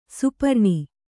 ♪ suparṇi